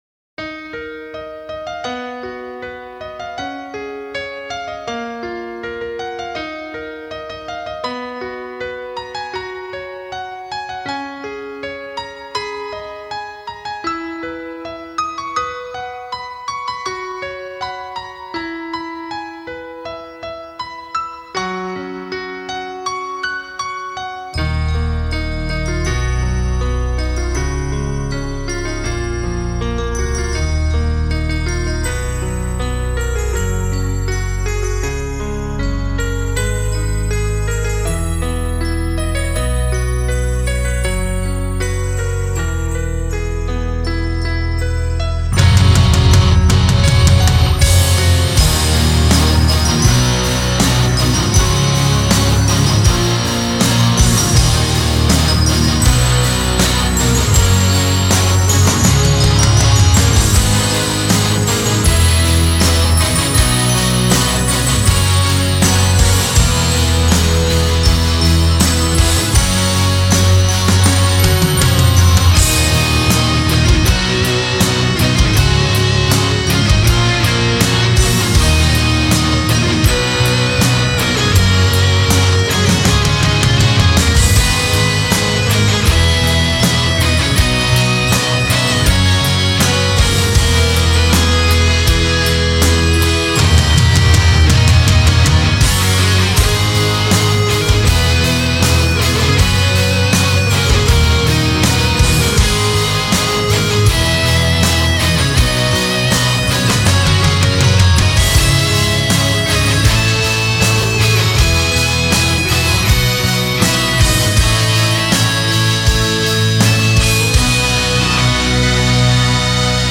2周前 纯音乐 5